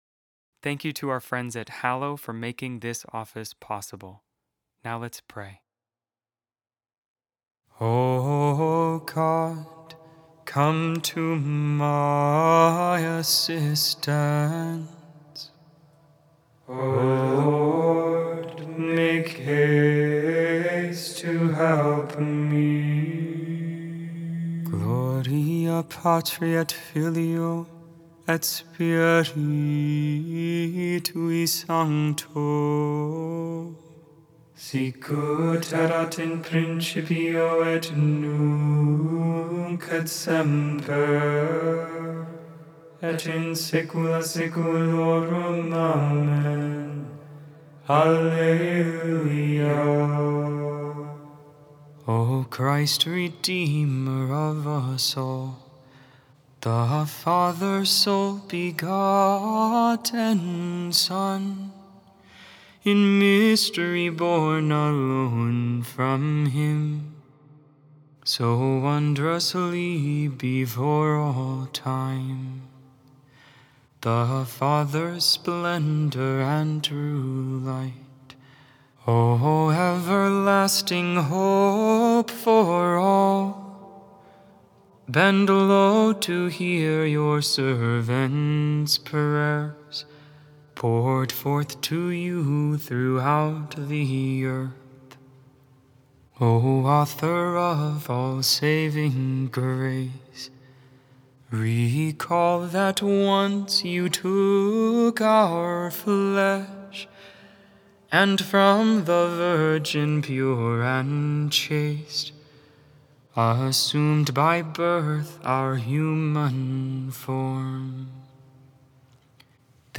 Vespers, Tuesday Evening Prayer December 30th, 2025. 6th Day in the Octave of ChristmasMade without AI. 100% human vocals, 100% real prayer.